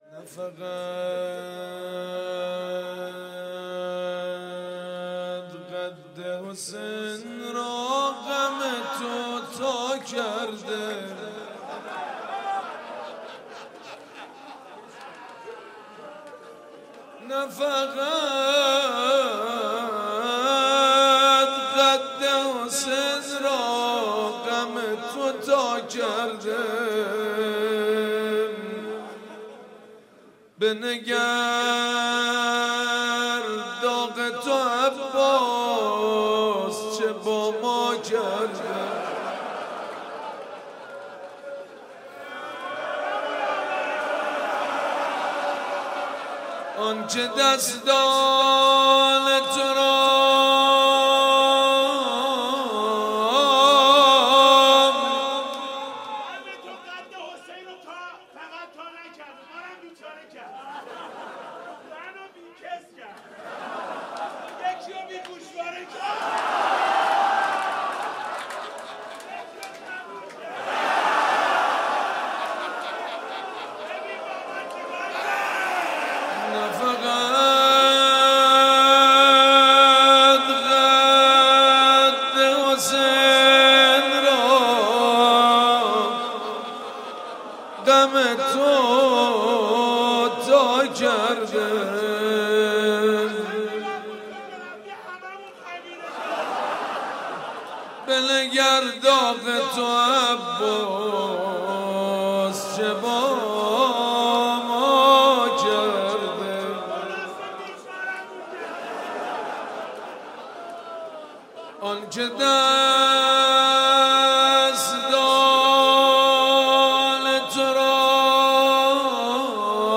• روضه حضرت ابوالفضل علیه السلام